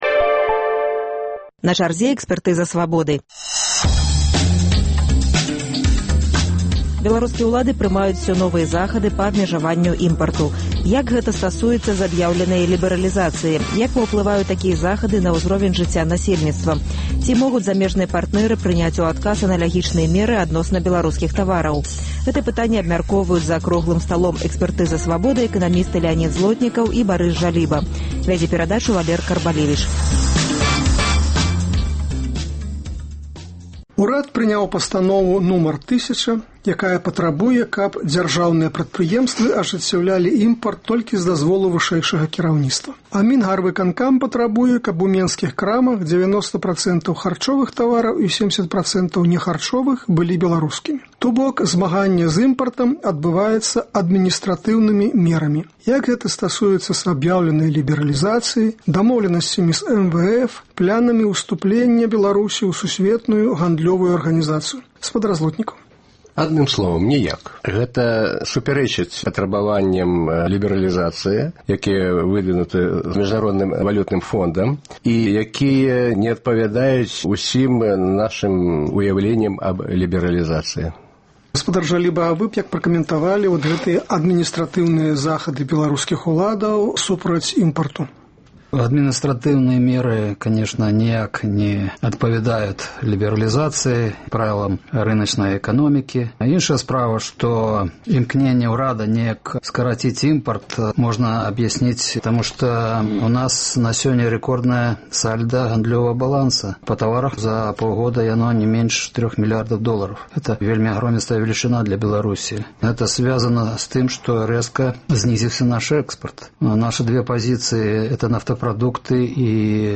Гэты пытаньні абмяркоўваюць эканамісты